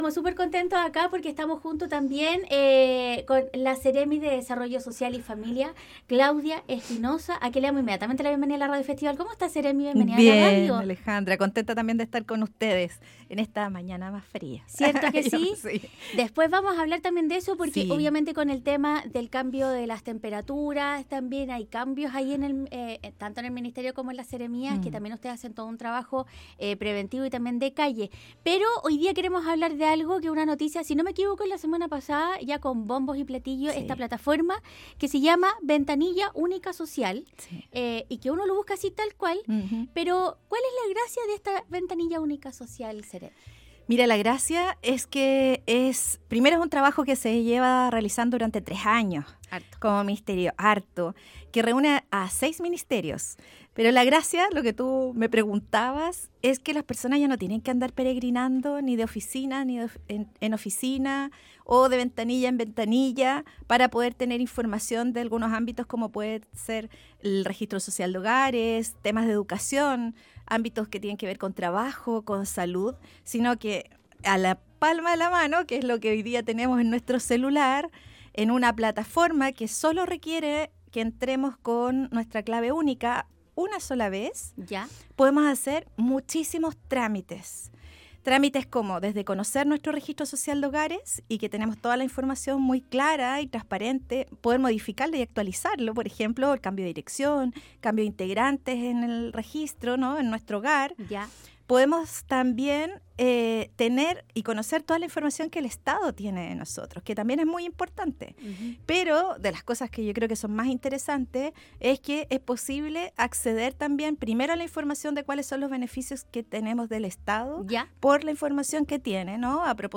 La Seremi de Desarrollo Social y Familia, Claudia Espinoza conversó con Radio Festival para explicar como es el funcionamiento de la nueva página web que abarca todos los trámites ciudadanos en linea